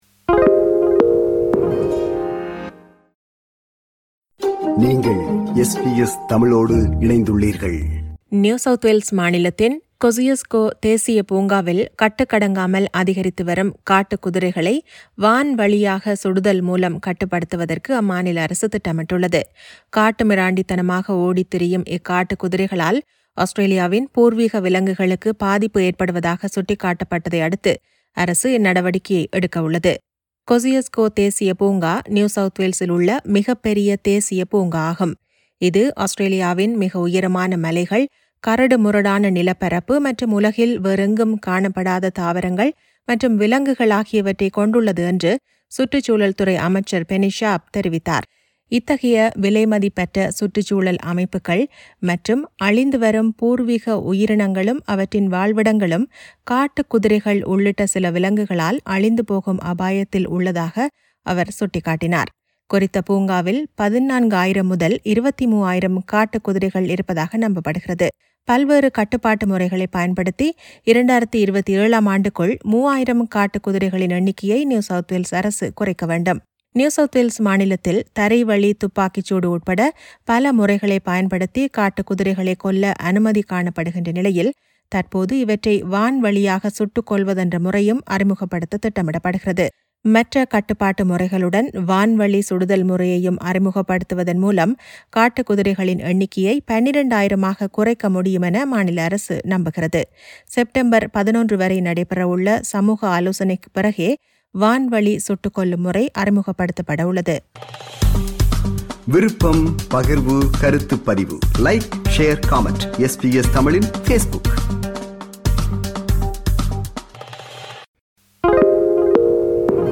நியூ சவுத் வேல்ஸ் மாநிலத்தின் Kosciuszko தேசிய பூங்காவில் கட்டுக்கடங்காமல் அதிகரித்துவரும் காட்டுக்குதிரைகளை வான்வழியாக சுடுதல் மூலம் கட்டுப்படுத்துவதற்கு அம்மாநில அரசு திட்டமிட்டுள்ளது. இதுகுறித்த செய்தி விவரணத்தை முன்வைக்கிறார்